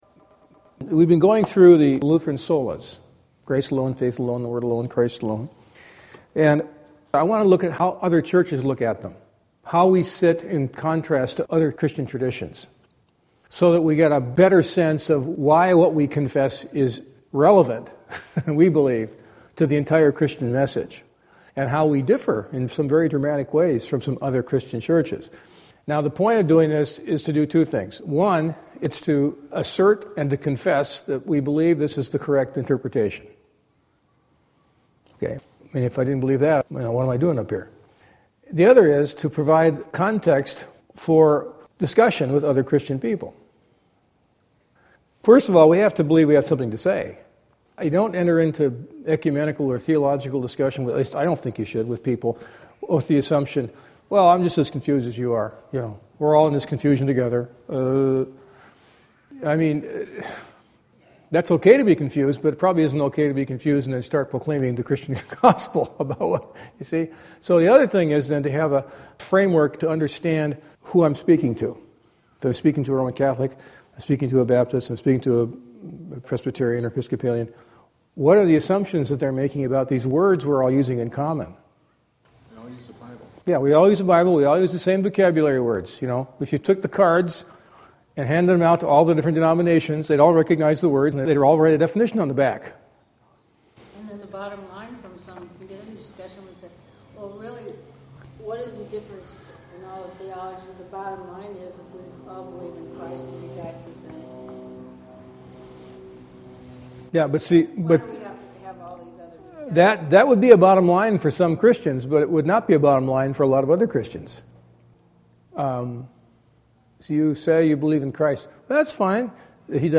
Quite an interesting and informative class on how differing Christian traditions view 'faith'.